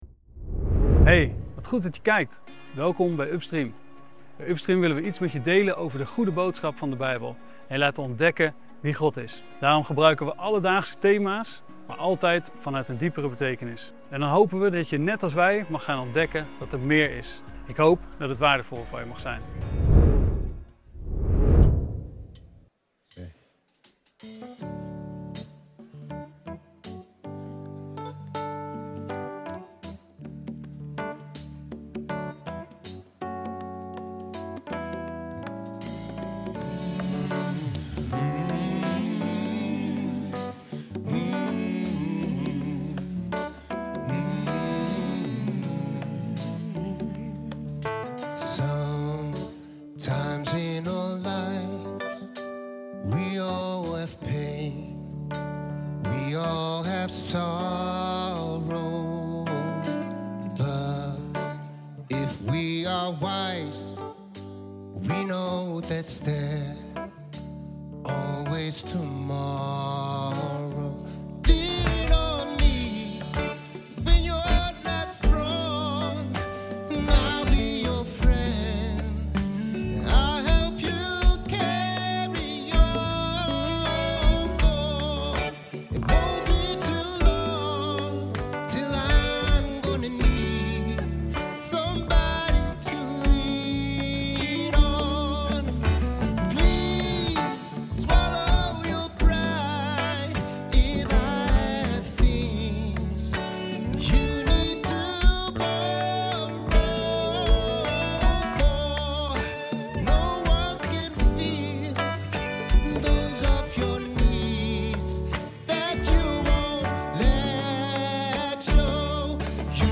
Upstream is een beleving met professionele muziek, een inspirerende boodschap en persoonlijke verhalen rondom een relevant thema. Laat je verrassen hoe eeuwenoude Bijbelse levenswijsheden een verrassend frisse blik kunnen geven op jouw situatie.